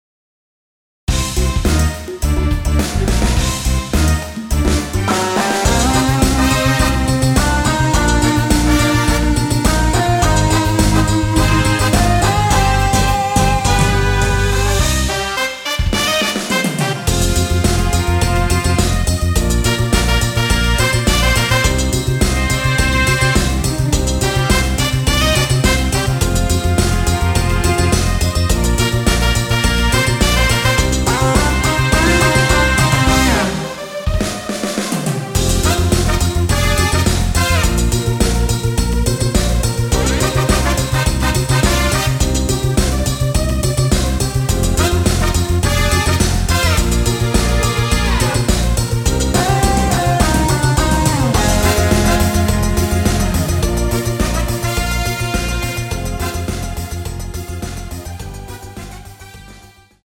원키 멜로디 포함된 MR입니다.
Cm
앞부분30초, 뒷부분30초씩 편집해서 올려 드리고 있습니다.